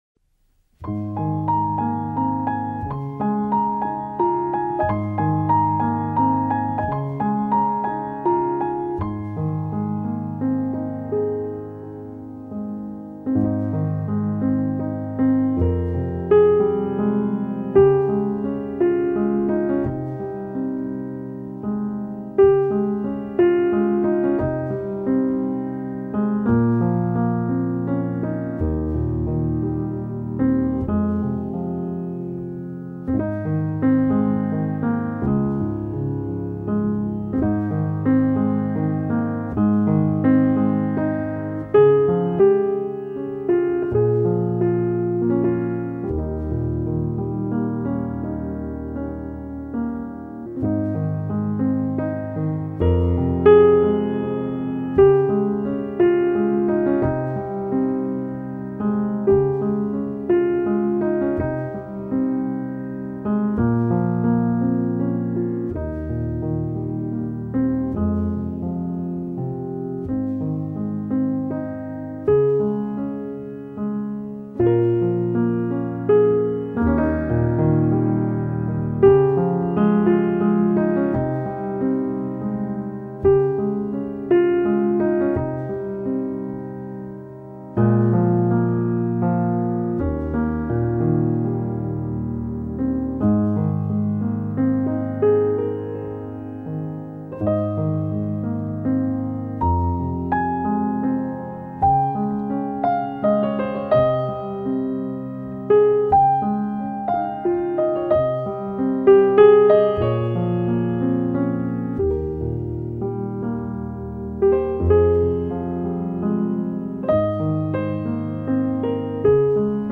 淡淡哀愁的鋼琴音色，鋪陳一段段邂逅、愛與離別的故事，
部分曲子加入弦樂、吉他、手風琴等樂器，呈現更豐富的音樂氛圍。
用最溫柔、平和的曲調表現出來。